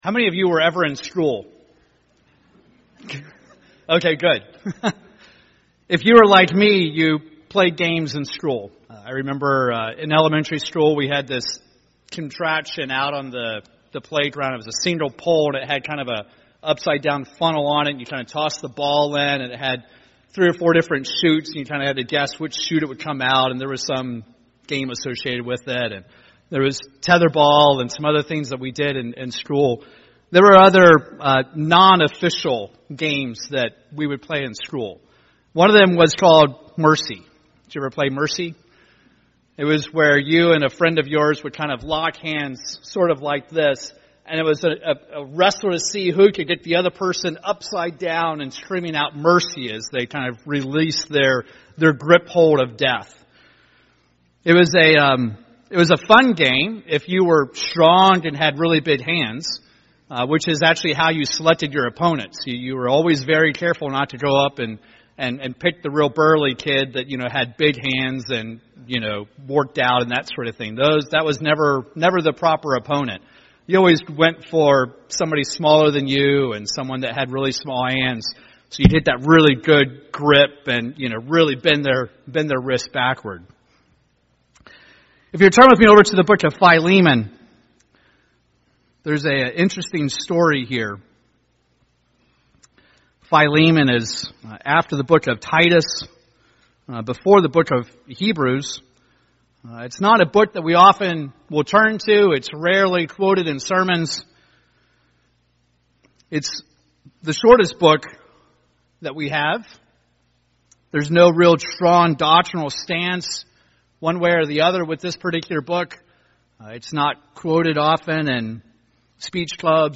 In this sermon we discuss the topic of mercy as it relates to the process of conversion.